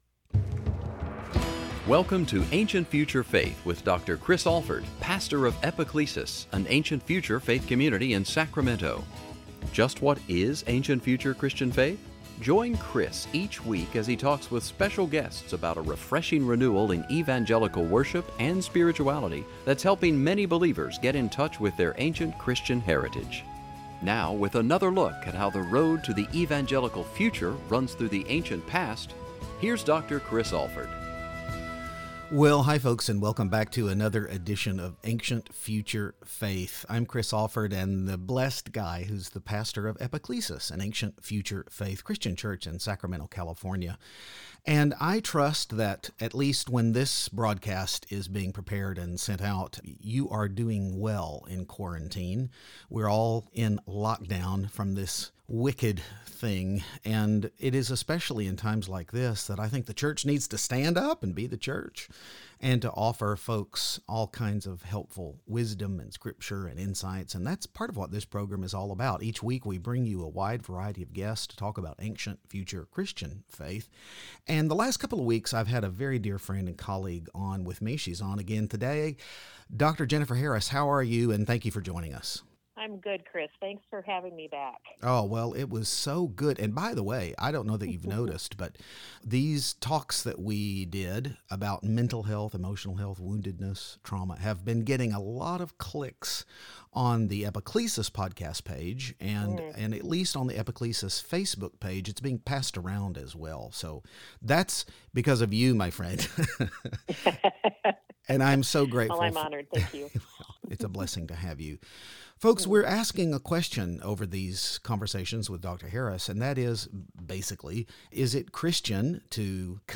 What do we look for, what do we do, when someone comes into our church with unresolved woundedness? And, also, how can the pastor's own woundedness affect the church? Join us for this much-needed conversation.